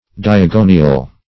Diagonial \Di`a*go"ni*al\, a.